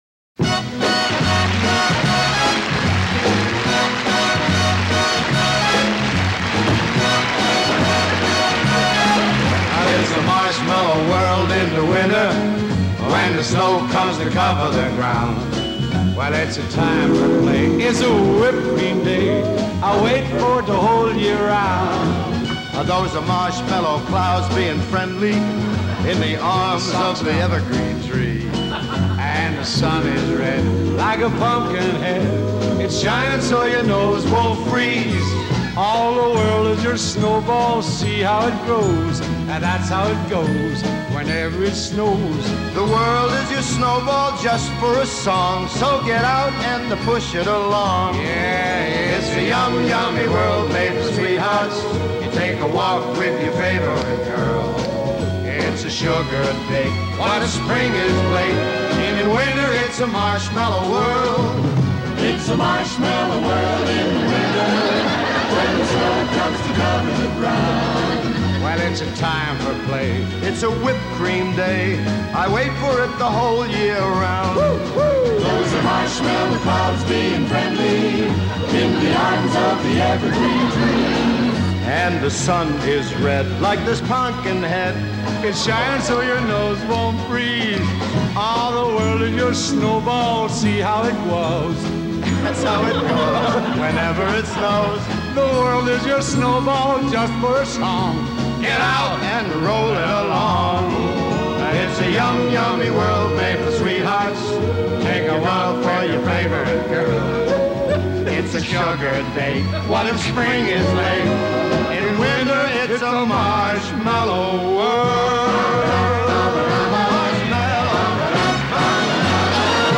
Today we have a live recording of